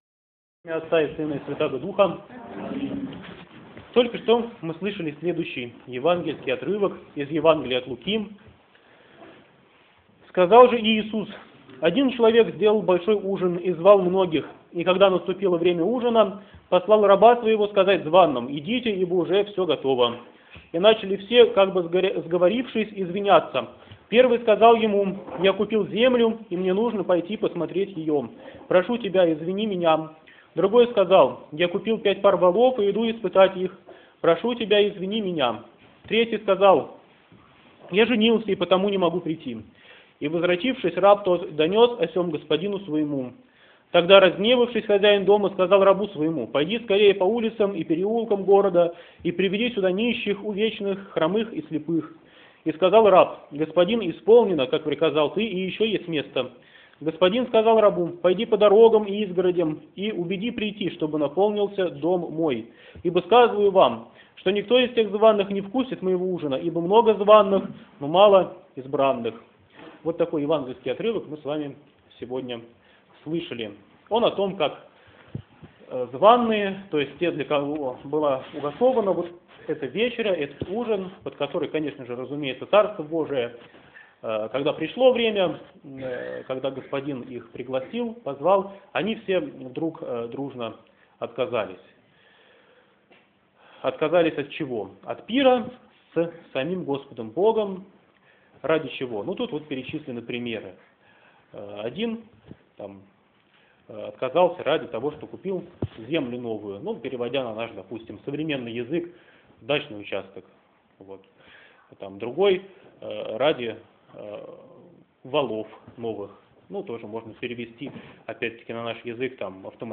Проповедь в Неделю 27-ю по Пятидесятнице